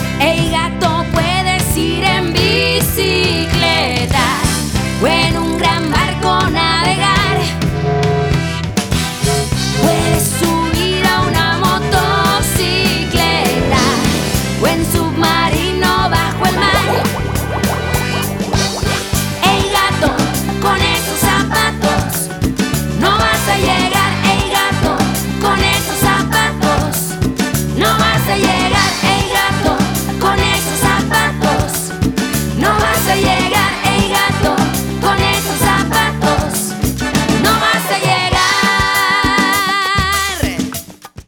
In 2007, she released another new children's album.